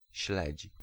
Ääntäminen
Ääntäminen Tuntematon aksentti: IPA: /ɕlɛt͡ɕ/ Haettu sana löytyi näillä lähdekielillä: puola Käännös Ääninäyte Substantiivit 1. herring US 2. tent peg Suku: m .